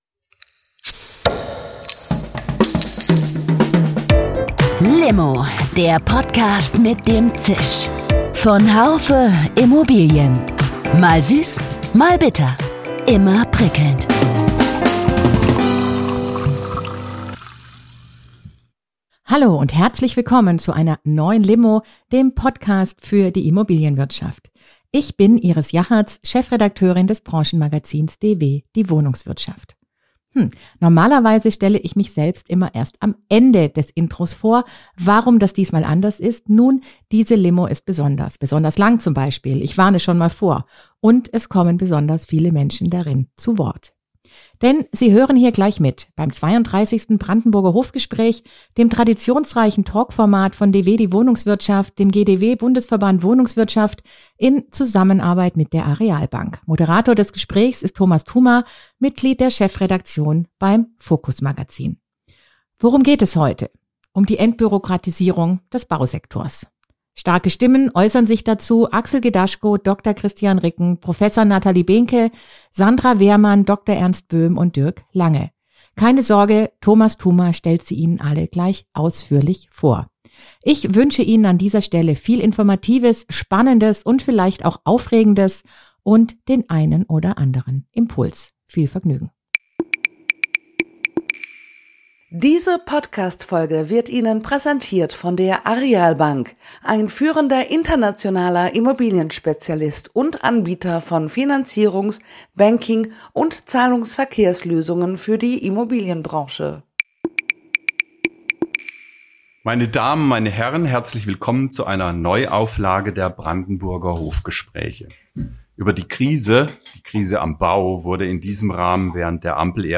Wie können wir Genehmigungsverfahren beschleunigen, Investitionen erleichtern und gleichzeitig Qualität sowie Nachhaltigkeit sicherstellen? Diese Fragen standen im Fokus des 32. Brandenburger Hof Gesprächs in Berlin, welches am 4. November 2025 stattfand, zwischen sechs Entscheidungsträgern aus Wohnungswirtschaft, Wissenschaft, Bauwirtschaft, Bankenwesen und Verwaltung. Die zentralen Thesen und Impulse aus der Diskussion zeigen: Es braucht nicht nur neue Gesetze, sondern auch einen echten Mentalitätswandel und mutige Entscheidungen.